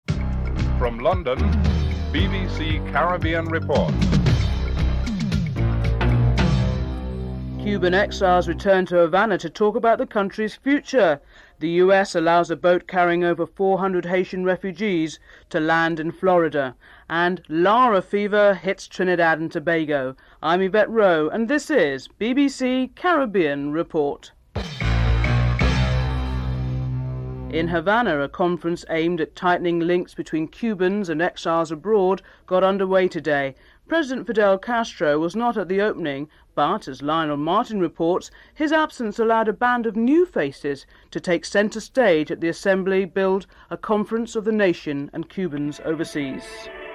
8. Wrap up and theme music (14:46-15:22)